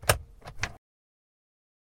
Descarga de Sonidos mp3 Gratis: abrir guantera.
glove-open-box.mp3